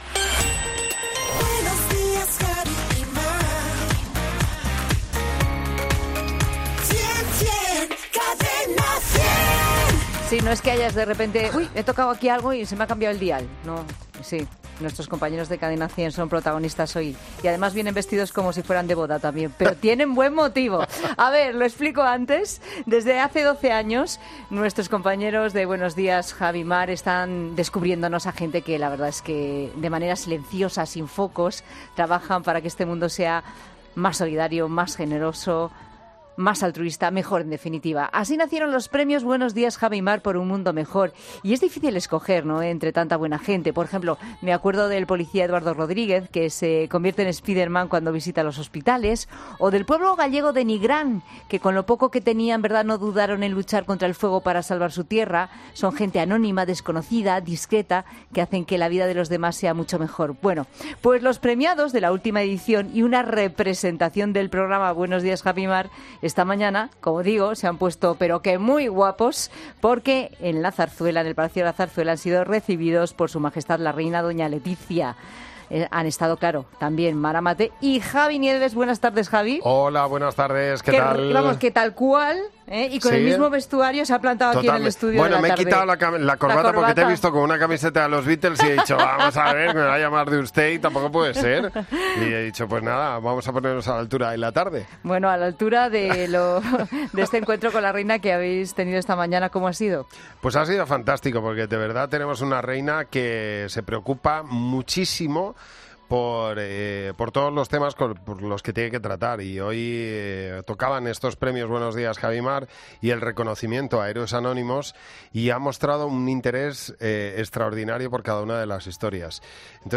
El presentador de ‘Buenos días Javi y Mar’, Javi Nieves ha pasado por los micrófonos de ‘La Tarde’ para contarnos cómo ha sido la mañana: “Ha sido fantástico.